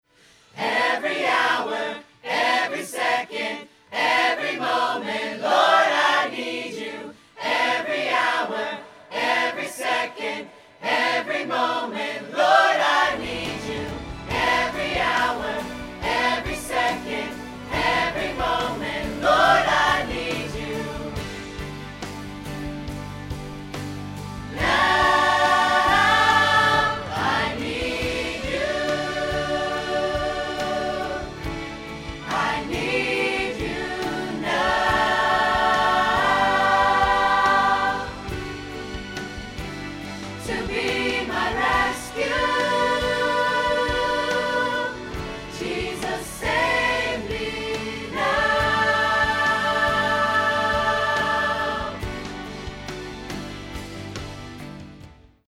• 0:00 – 0:06 – Choir Mics Soloed
• 0:07 – 0:13 – Choir Mics with Reverb
• 0:13 – 0:55 – Choir Mics with Backing Track
Shure SM58